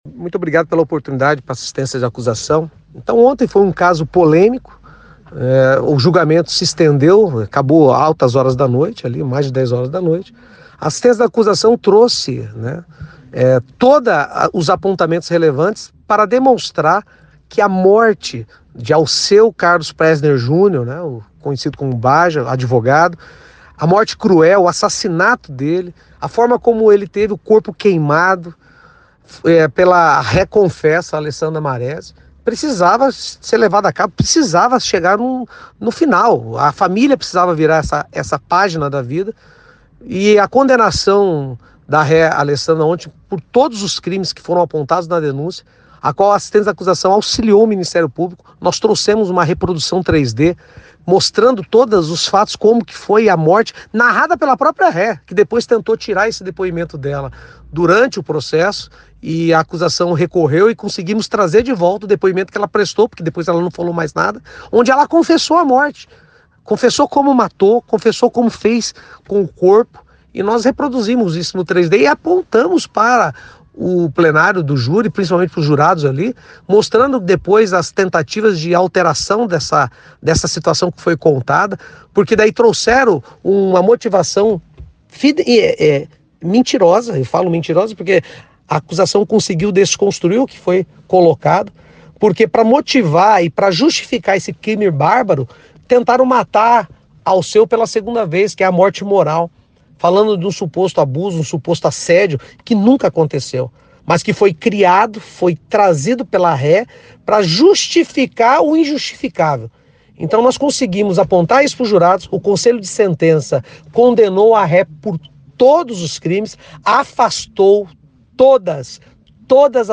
Em entrevista à CBN nesta sexta-feira